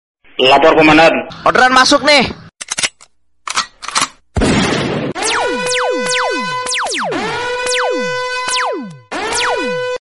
𝐍𝐎𝐓𝐈𝐅𝐈𝐊𝐀𝐒𝐈 𝐖𝐇𝐀𝐓𝐒𝐀𝐏𝐏 𝐎𝐑𝐃𝐄𝐑𝐀𝐍 𝐌𝐀𝐒𝐔𝐊 𝐂𝐎𝐂𝐎𝐊